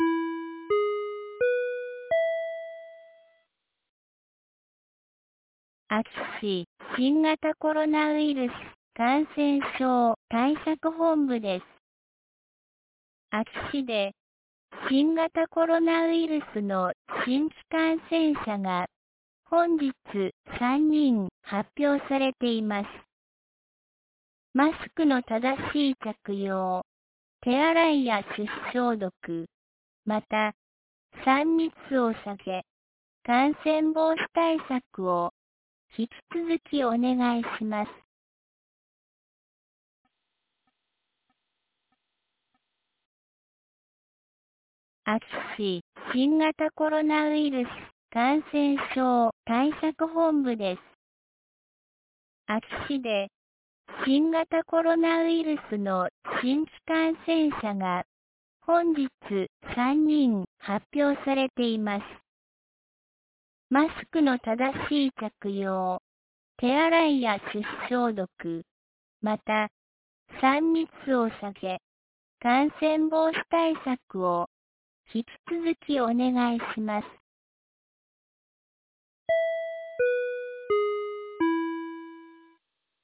2022年09月20日 17時06分に、安芸市より全地区へ放送がありました。